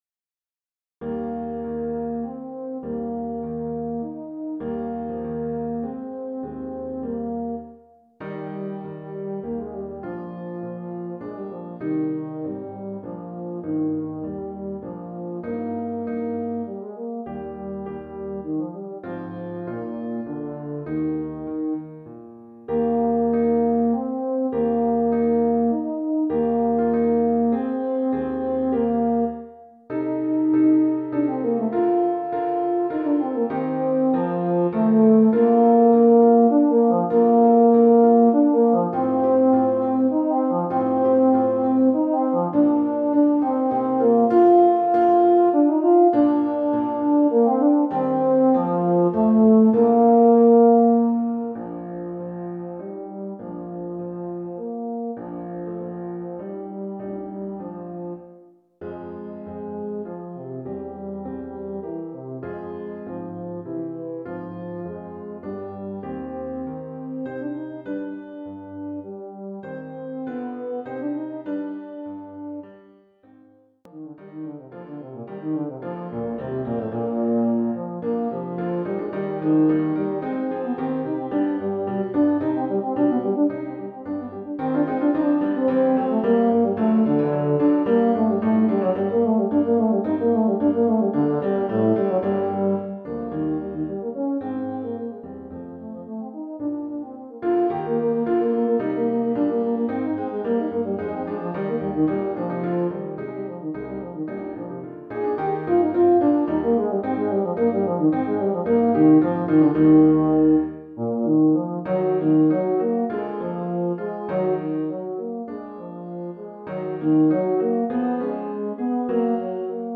Voicing: Euphonium and Piano